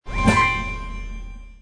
level_win.mp3